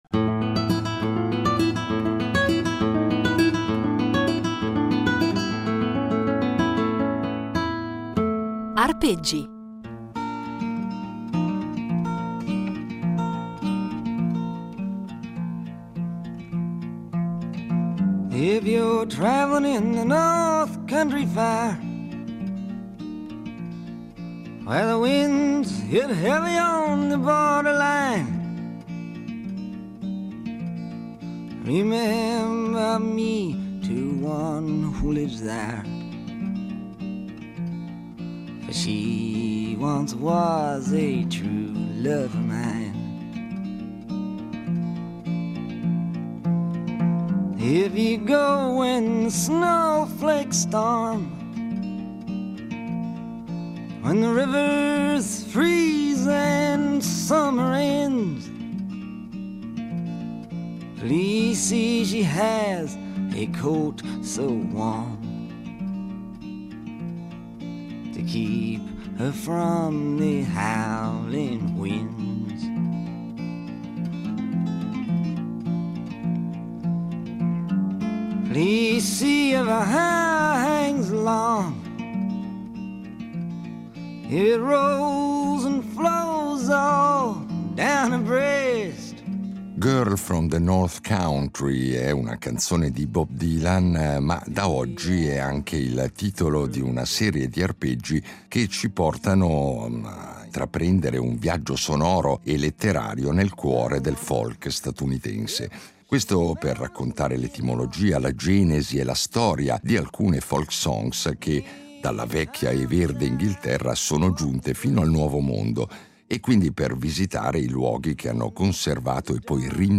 Non solo, però, perché i nostri itinerari sonori saranno impreziositi dalle esecuzioni “live”, e quindi inedite, del trio Folkways